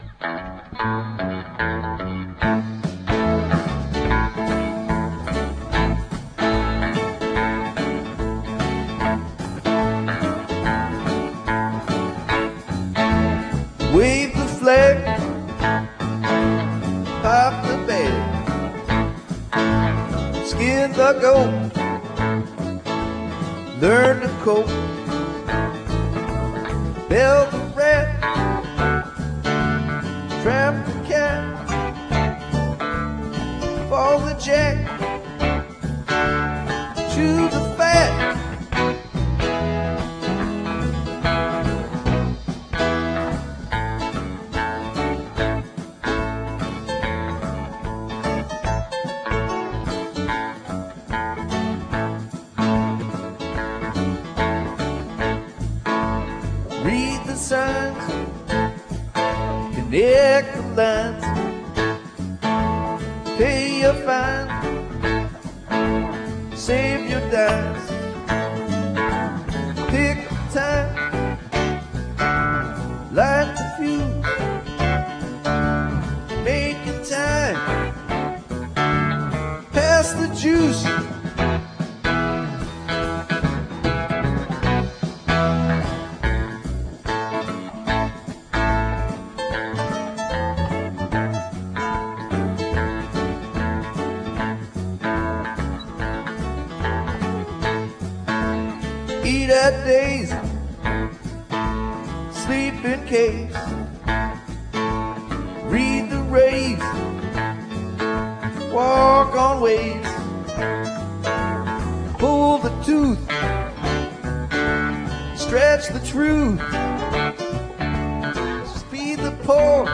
practice session from 1-4-99